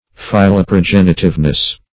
Search Result for " philoprogenitiveness" : The Collaborative International Dictionary of English v.0.48: Philoprogenitiveness \Phil`o*pro*gen"i*tive*ness\, n. [Philo- + L. progenies offspring.]